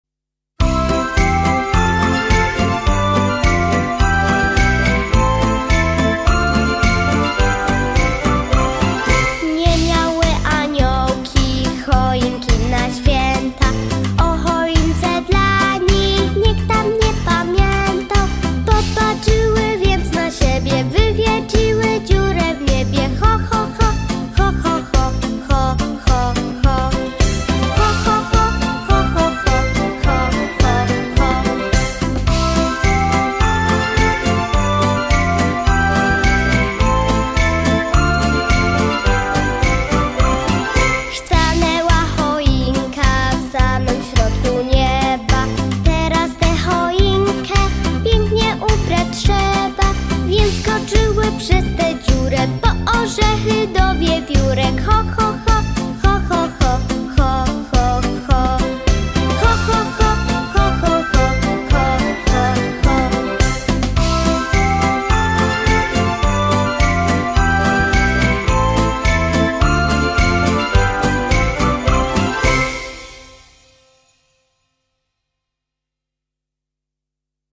Nie-mialy-aniolki-Koledy-dla-dzieci-Podklad-Jangok.mp3